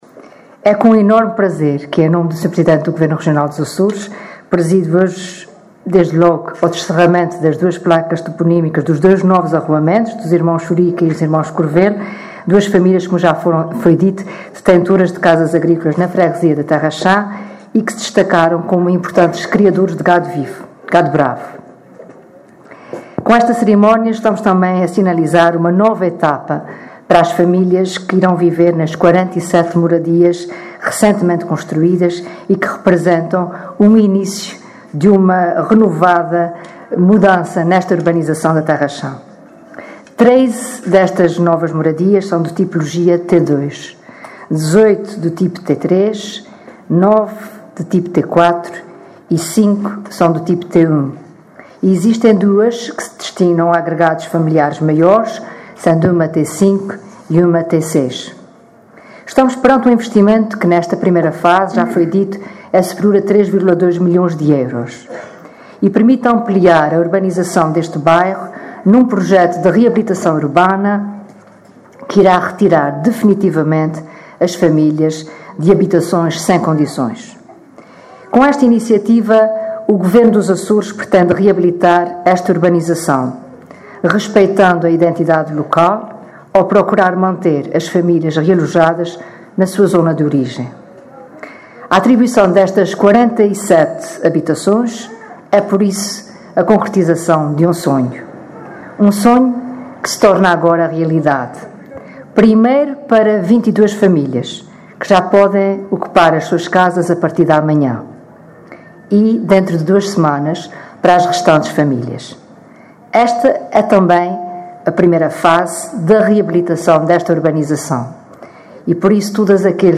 A Secretária Regional da Solidariedade Social disse hoje, na Terra Chã, concelho de Angra do Heroísmo, que o Governo dos Açores está a “cumprir o compromisso assumido" de continuar a apoiar a autonomização habitacional das famílias, seja através de realojamento ou do programa Famílias com Futuro, que beneficia atualmente mais de mil agregados familiares.
Piedade Lalanda, que falava na cerimónia de descerramento de placas toponímicas em dois novos arruamentos daquela freguesia, salientou que se está a iniciar agora “uma nova etapa para as famílias que irão viver nas 47 moradias” recentemente construídas, que representam o “início desta renovada mudança na urbanização da Terra Chã”.